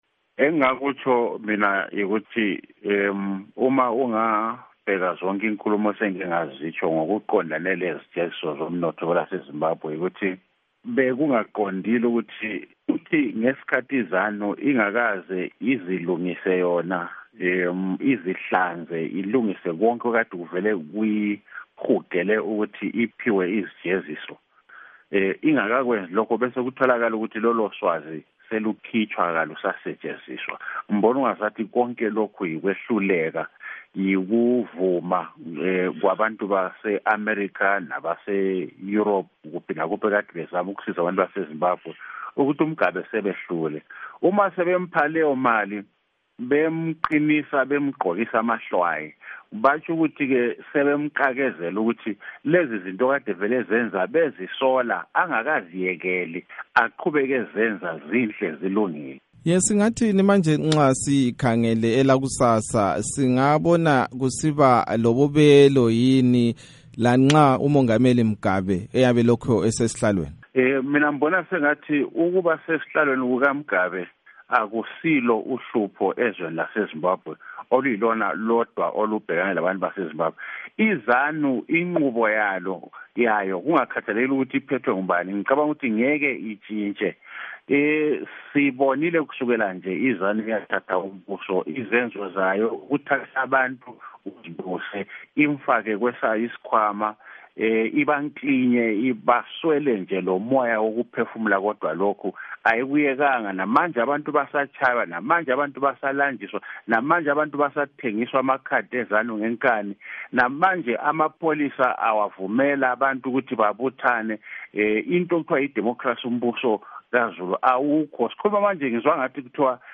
Ingxoxoxo loMnu.